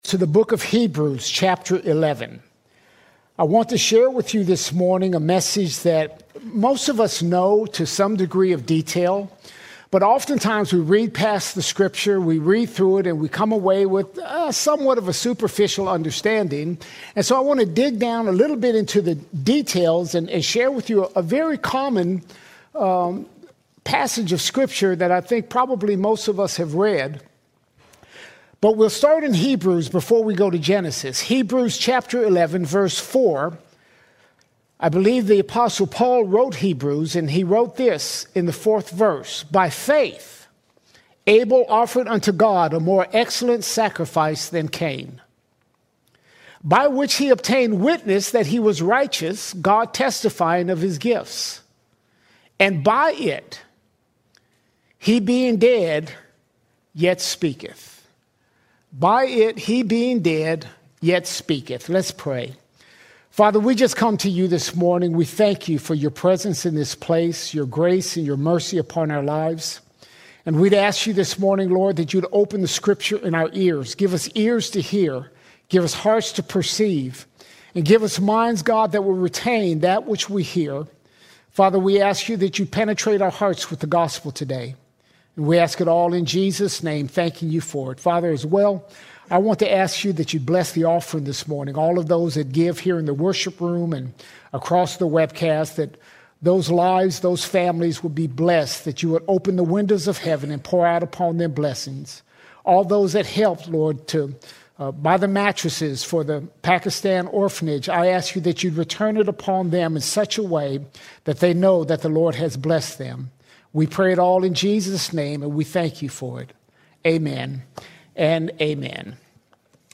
16 February 2026 Series: Sunday Sermons All Sermons The Blood Is Required The Blood Is Required From the beginning, the blood was required.